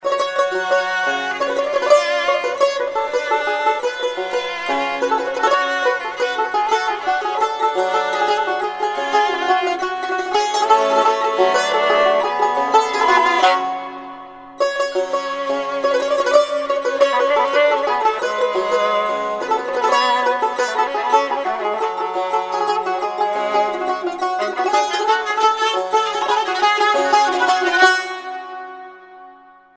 زنگ موبایل ملایم و بی کلام
(موسیقی سنتی)